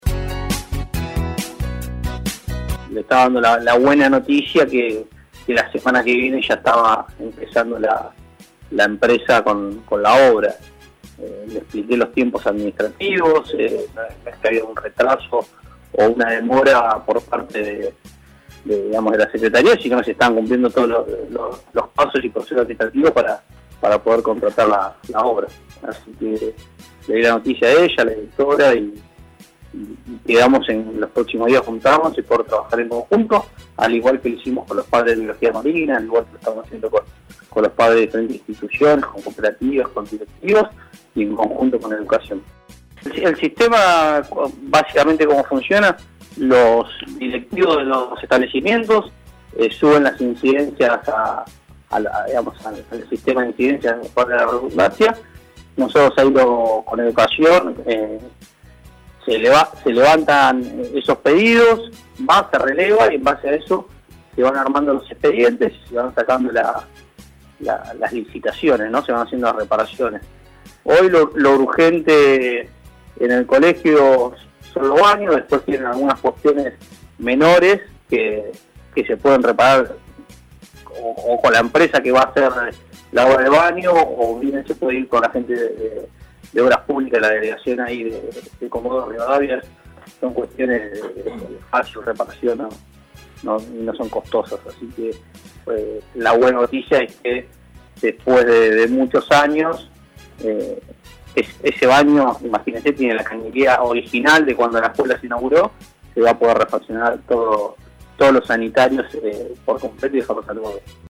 Conceptos de Hernán Tórtola, secretario de Infraestructura, Energía y Planificación de Chubut: